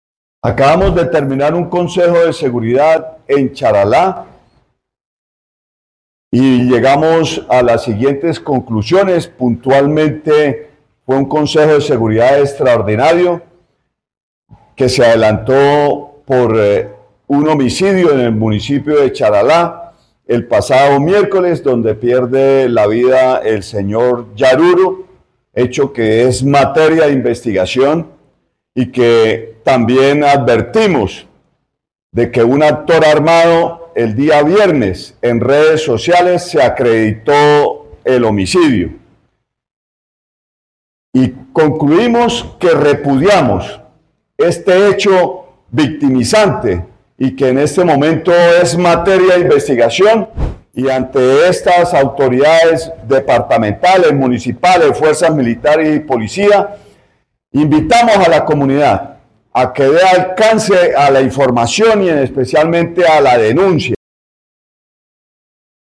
Óscar Hernández, secretario del Interior de Santander